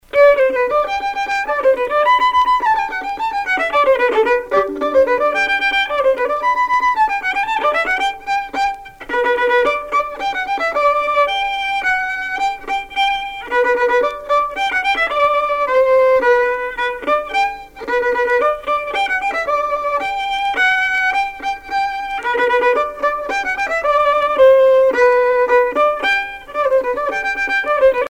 danse : pas d'été
Pièce musicale éditée